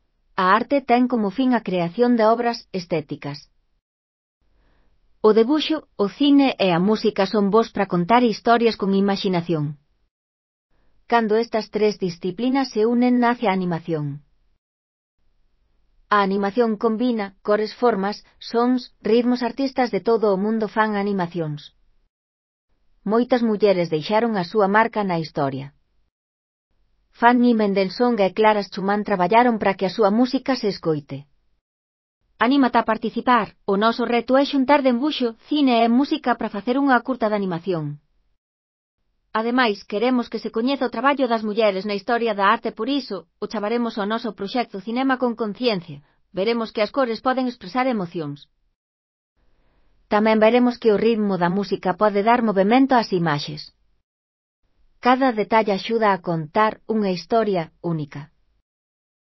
Elaboración propia (proxecto cREAgal) con apoio de IA, voz sintética xerada co modelo Celtia..